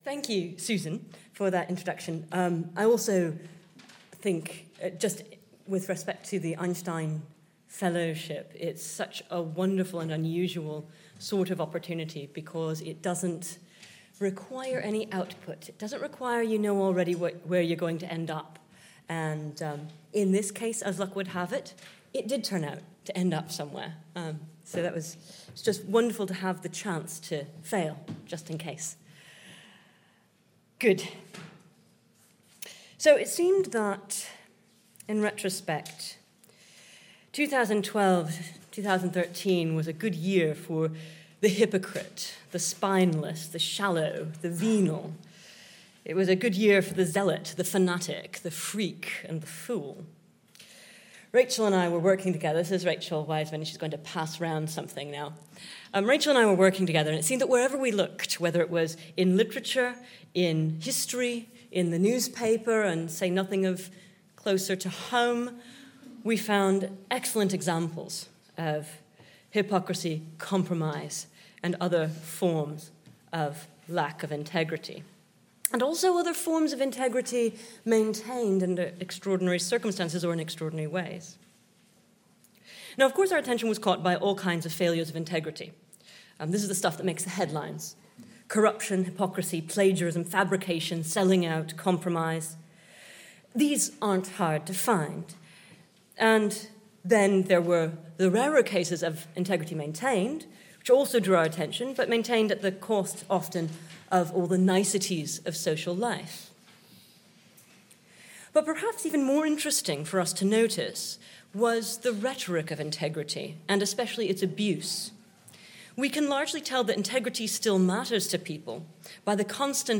The following paper was presented at Saints and Madmen: Integrity at its Limits, at the Einstein Forum in June 2014.